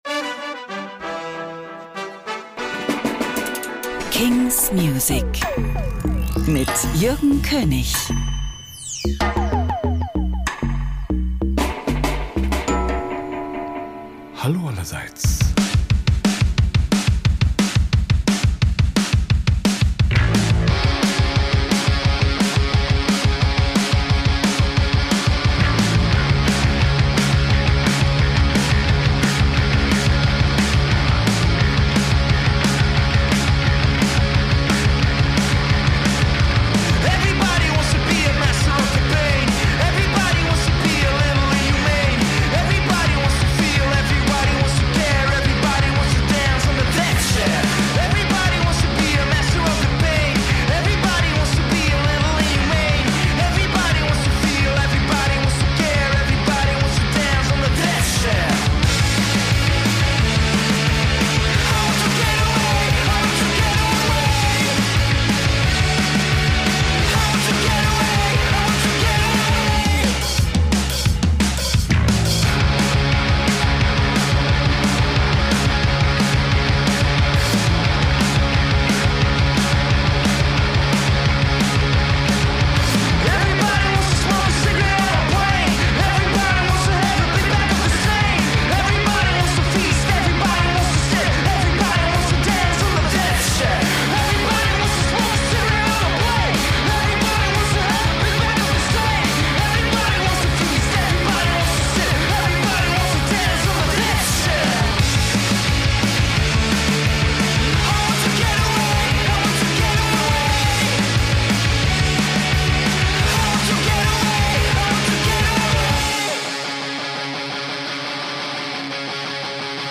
new indie & alternative releases.